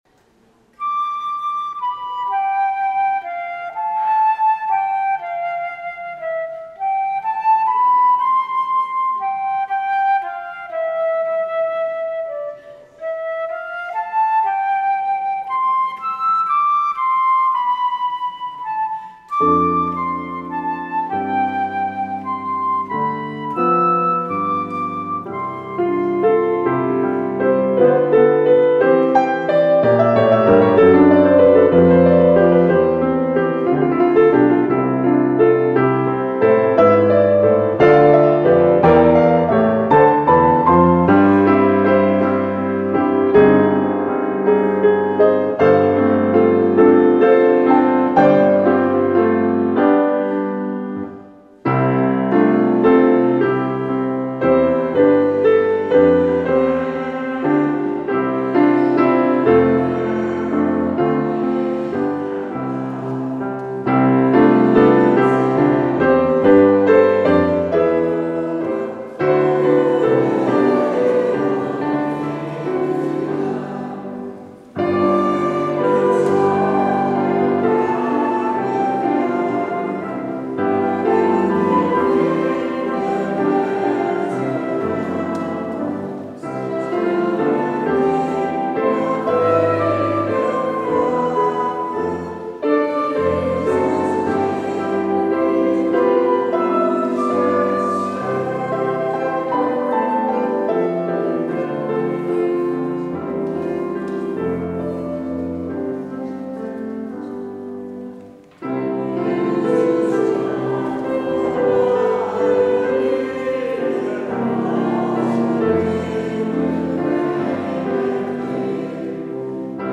 Luister deze kerkdienst hier terug: Alle-Dag-Kerk 27 december 2022 Alle-Dag-Kerk https
Het openingslied is: Liedboek 598, Als alles duister is. Als slotlied Liedboek 286, Waar de mensen dwalen in het donker.